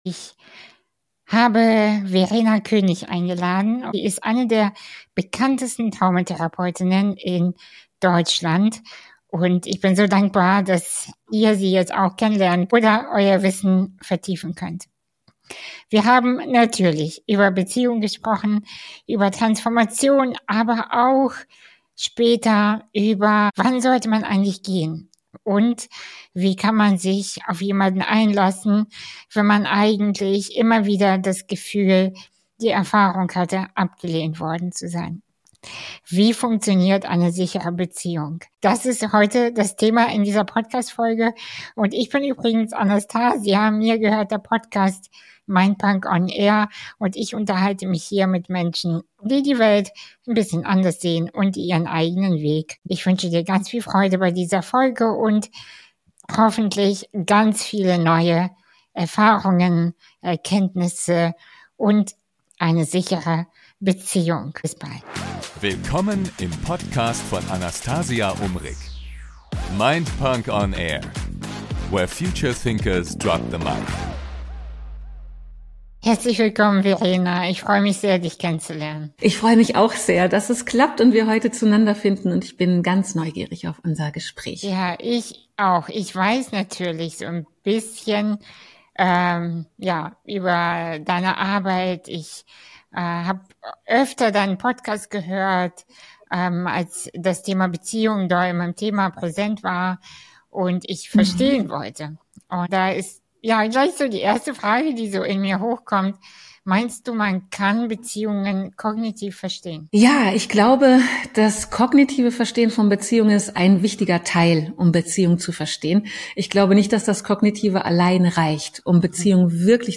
Eine ruhige, ehrliche und tiefe Folge für alle, die Beziehung nicht länger als Kampfplatz, sondern als bewussten Weg verstehen wollen.